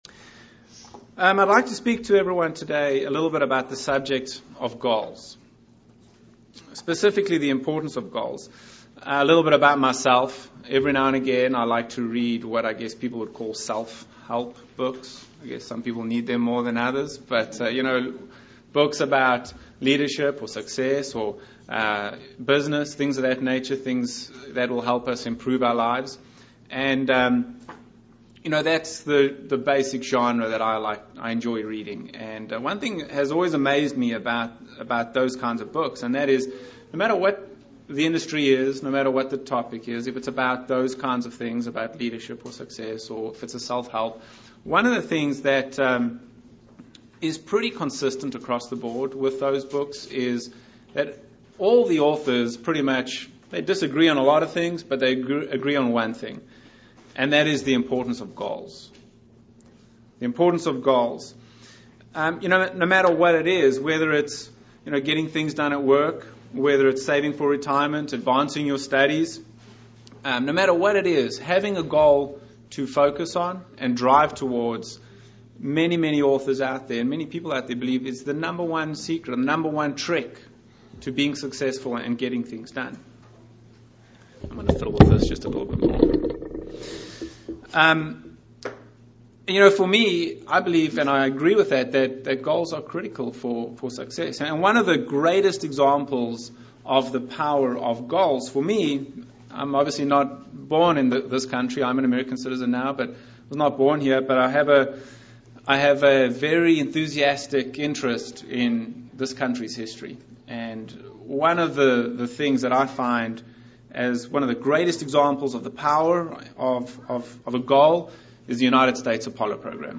Given in Lawton, OK
UCG Sermon Studying the bible?